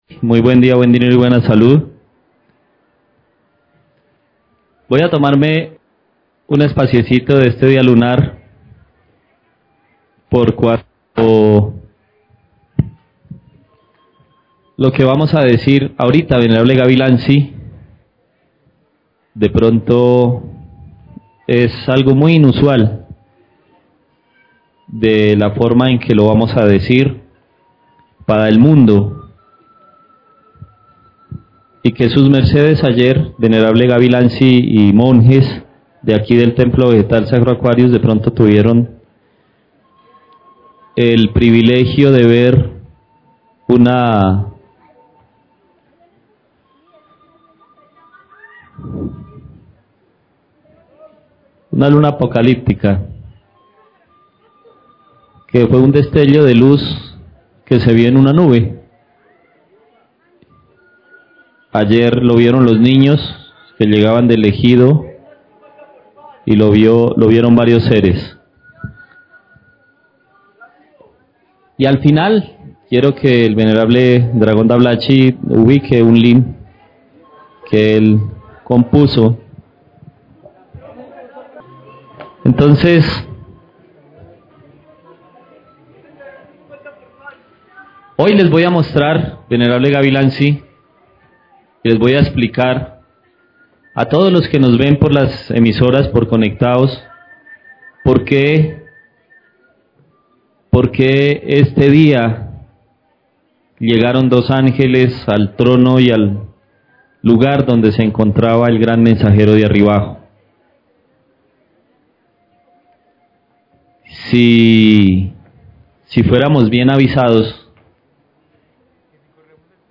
♪ Konferensia: ¿Porké dos Ángeles visitaron la esfera? Peligro en el Cambio de Luna